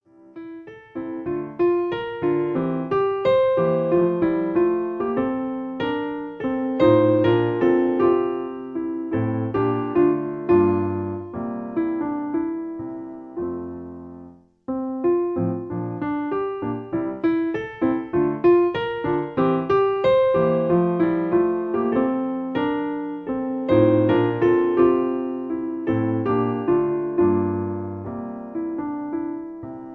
Piano accompaniment. In A